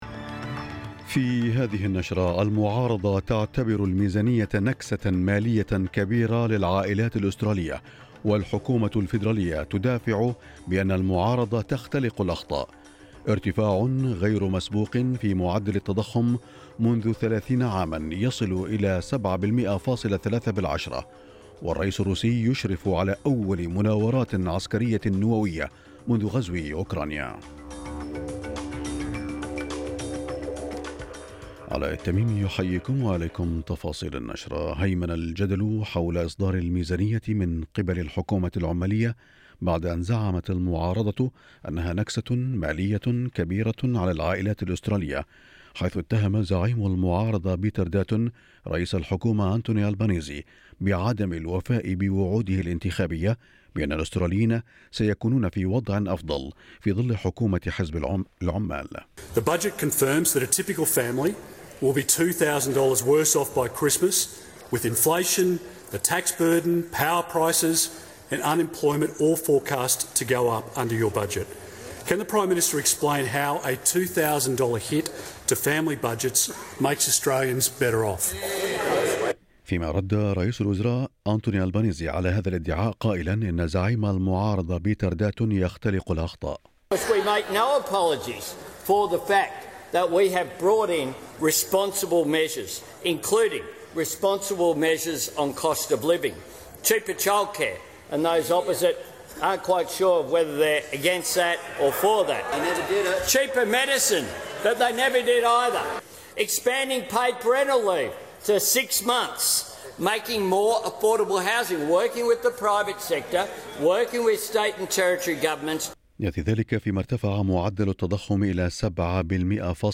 نشرة اخبار الصباح 27/10/2022